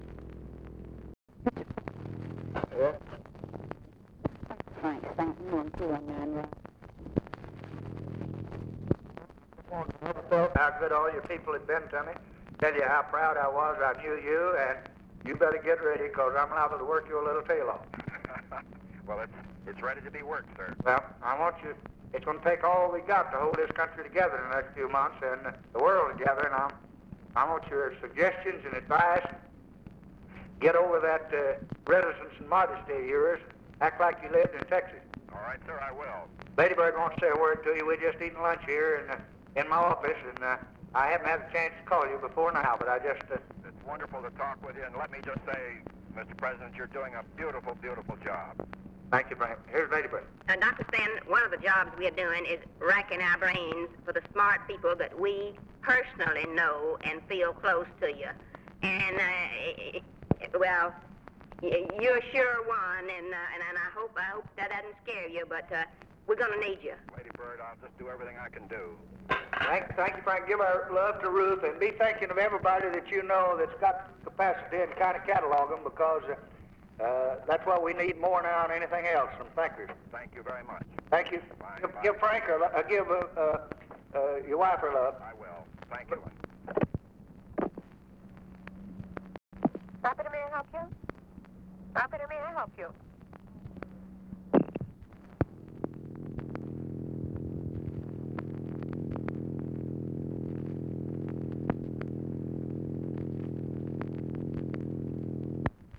Conversation with FRANK STANTON, November 27, 1963
Secret White House Tapes